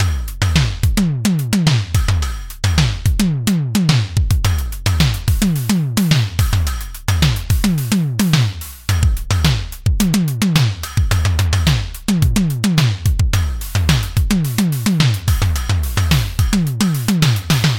Test drums programmed in FL by hand. No fx.